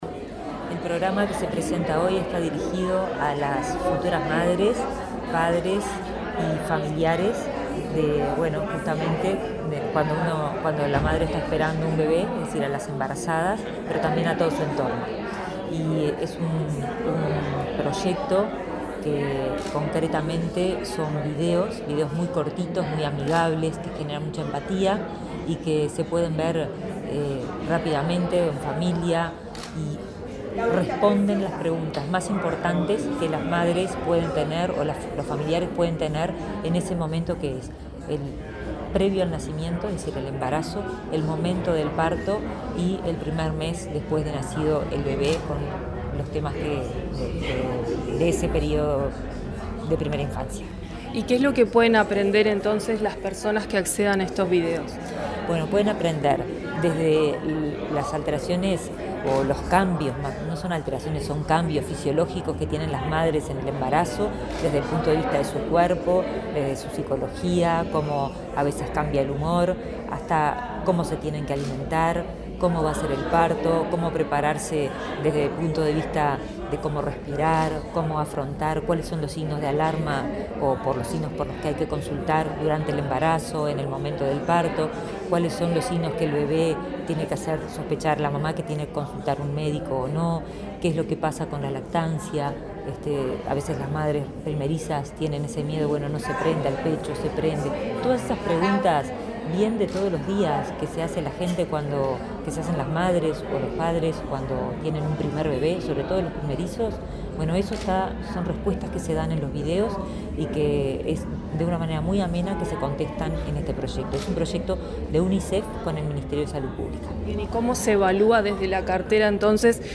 Entrevista a la ministra de Salud Pública, Karina Rando